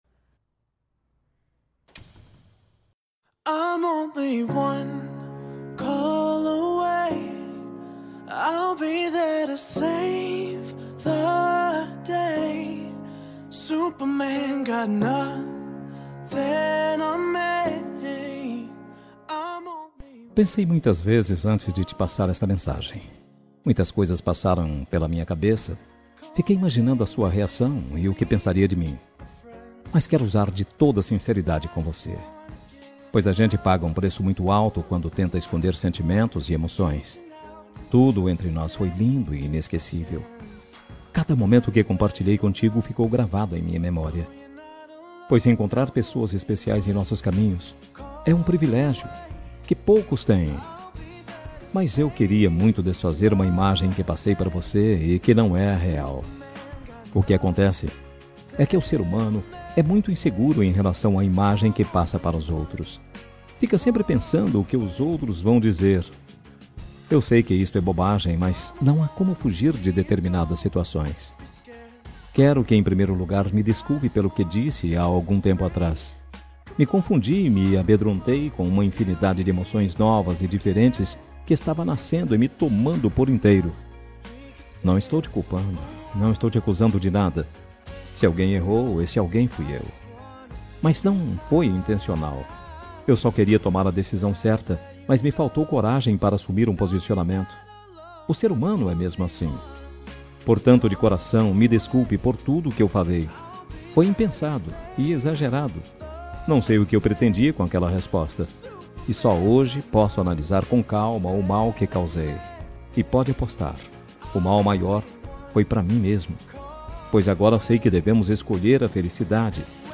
Telemensagem de Desculpas Geral – Voz Masculina – Cód: 5472